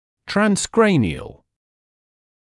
[ˌtræns’kreɪnɪəl], [trɑːn-][ˌтрэнc’крэйниэл], [траːн-]транскраниальный